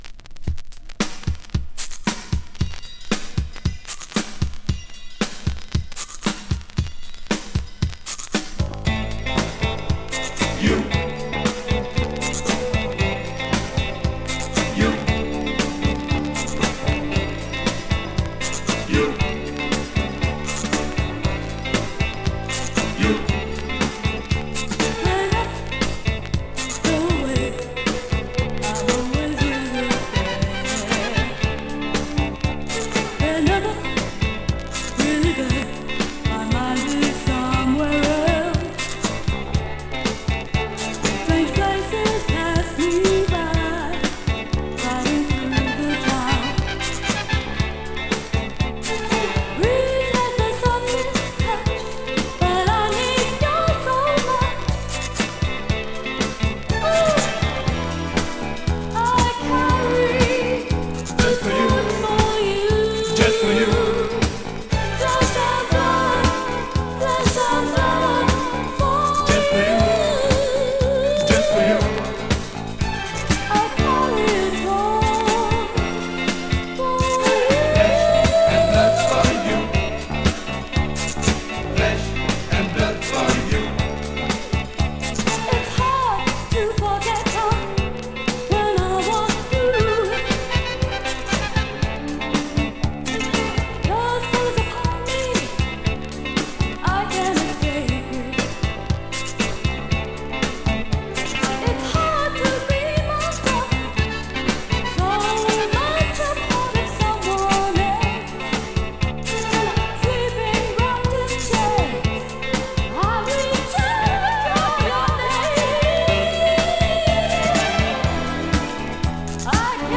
【NEW WAVE】 【DISCO】 【BELGIUM】
New wave funk from Belgium!
new wave disco numbers